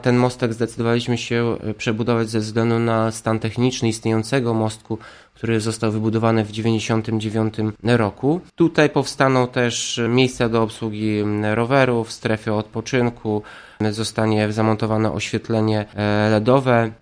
prezydent Ełku Tomasz Andrukiewicz